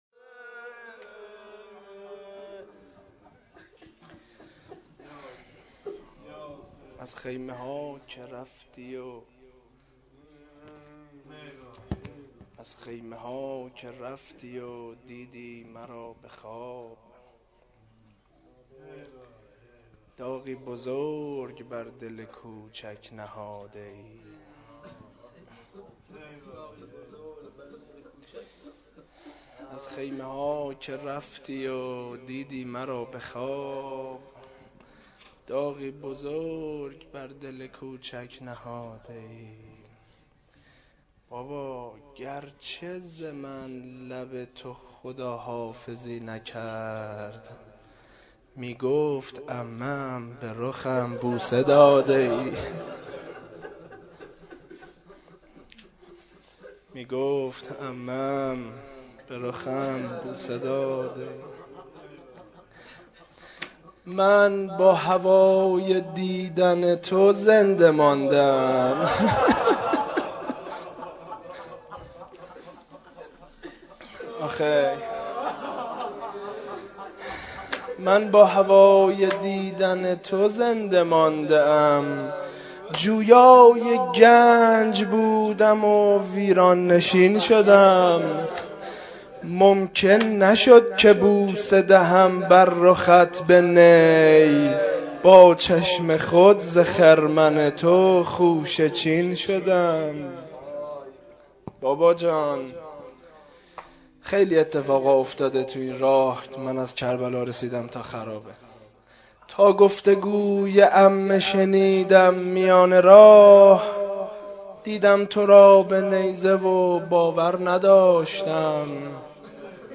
روضه حضرت رقیه (س)؛ مراسم هفتگی؛ 22 شوال 1429؛ قسمت دوم